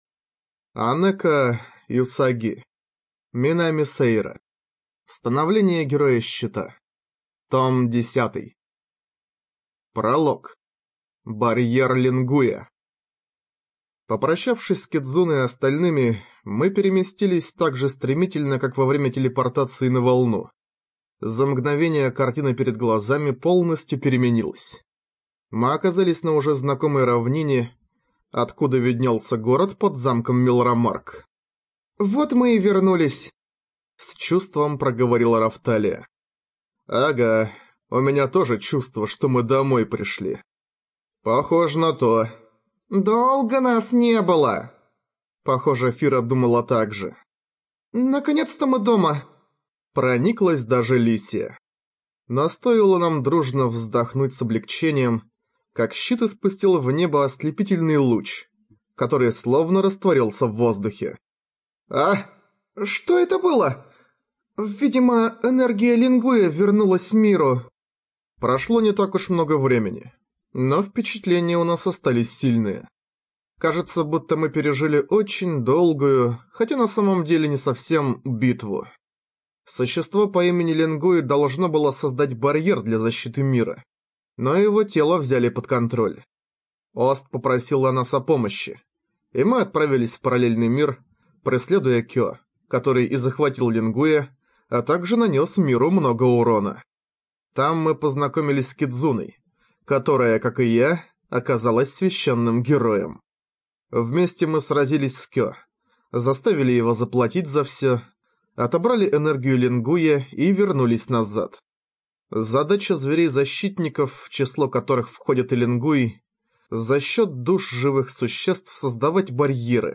ЖанрФэнтези
Эту и другие книги нашей библиотеки можно прослушать без использования компьютера с помощью Android-приложения или тифлофлешплеера с поддержкой онлайн-доступа.